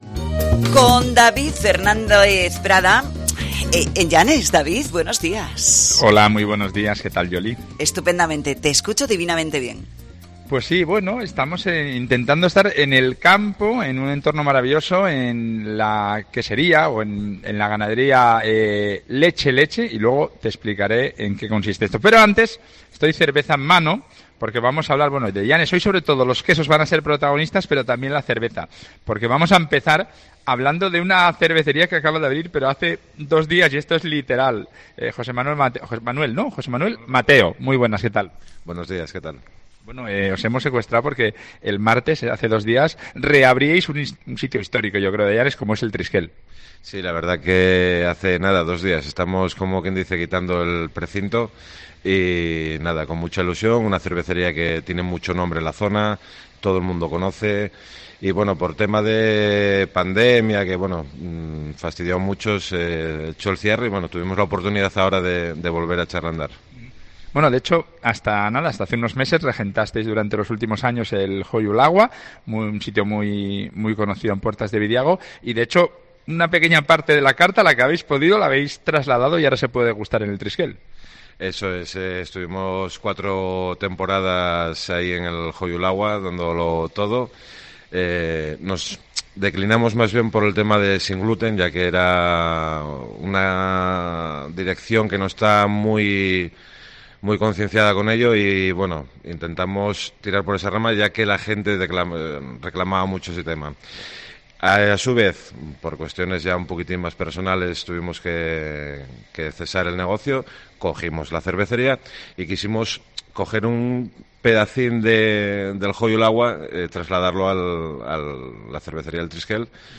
Programa especial desde Porrúa, Llanes, para poner en valor la región quesera del oriente asturiano.